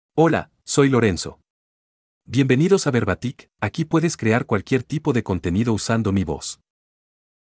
Lorenzo — Male Spanish (Chile) AI Voice | TTS, Voice Cloning & Video | Verbatik AI
MaleSpanish (Chile)
Lorenzo is a male AI voice for Spanish (Chile).
Voice sample
Listen to Lorenzo's male Spanish voice.
Lorenzo delivers clear pronunciation with authentic Chile Spanish intonation, making your content sound professionally produced.